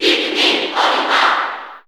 Crowd cheers (SSBU) You cannot overwrite this file.
Olimar_Cheer_Japanese_SSB4_SSBU.ogg